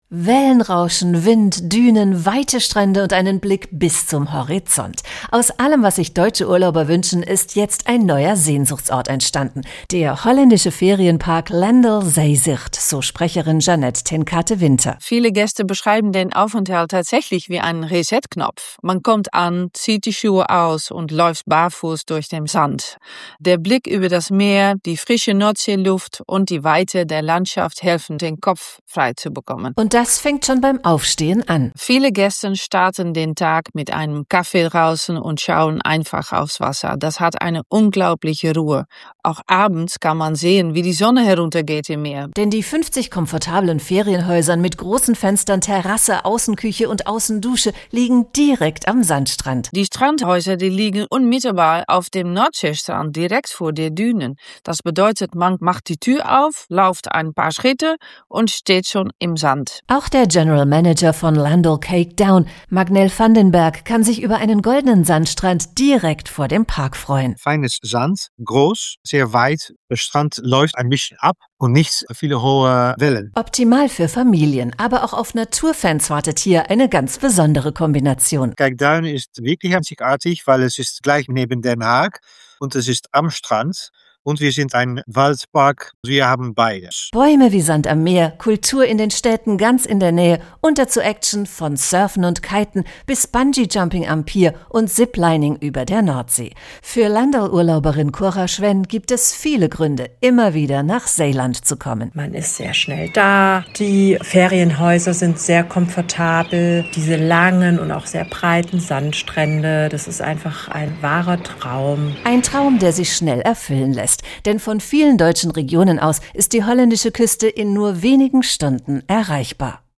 Radio-PR-Beitrag: "Meer geht immer" – Warum die niederländische Küste so gefragt ist